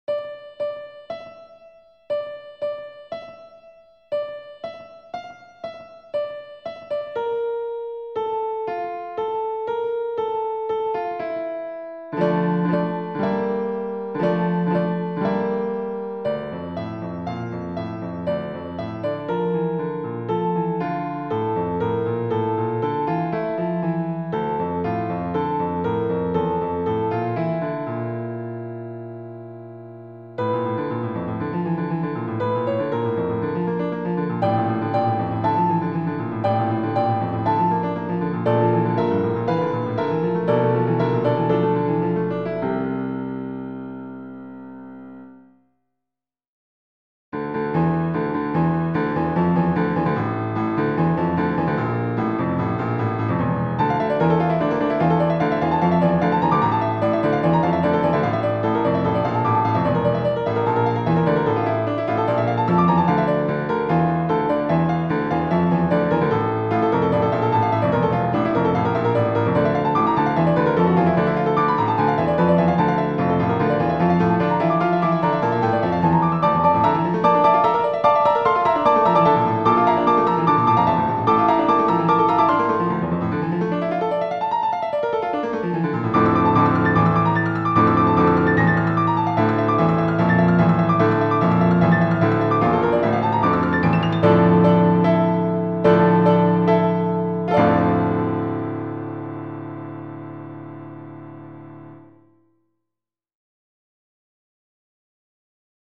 それでも耳に残ってしまうこの強烈で単調なフレーズ。
最初の数小節は完璧に耳コピーになってますねぇ…。半分くらい経ってから「さくらさくら」は伴奏になってます。